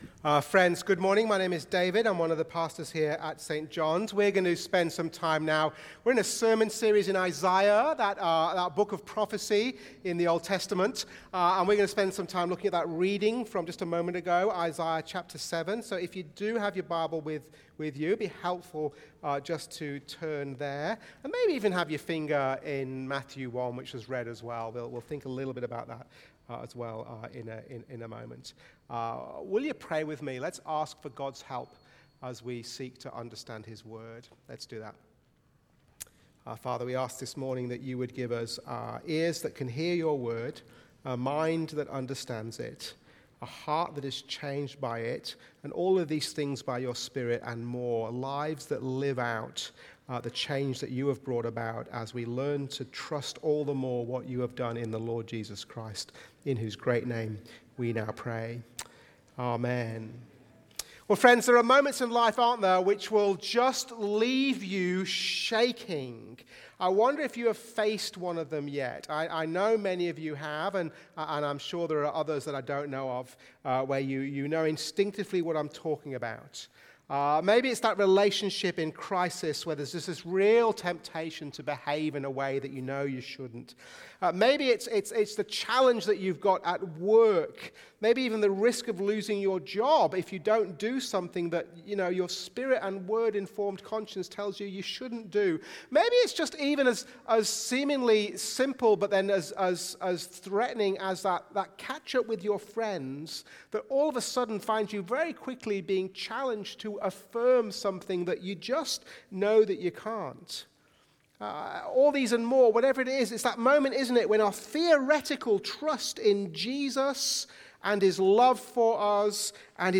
Sermons | St Johns Anglican Cathedral Parramatta
Watch the full service on YouTube or listen to the sermon audio only.